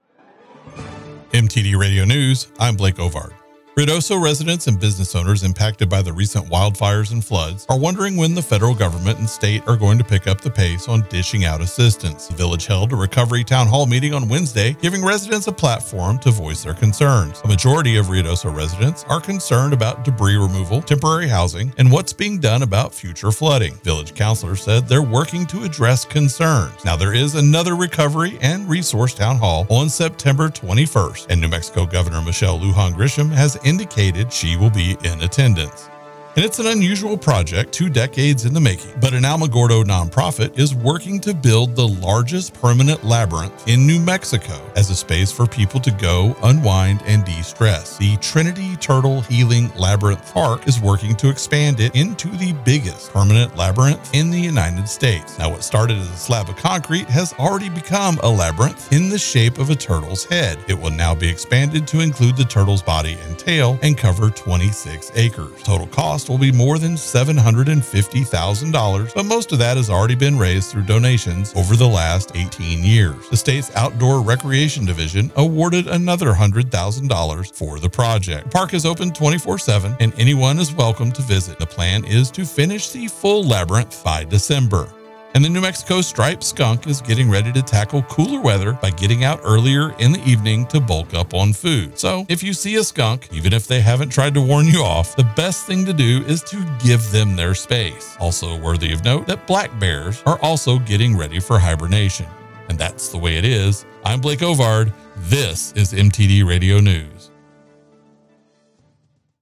MIX 96.7 NEWS RUIDOSO AND LINCOLN COUNTY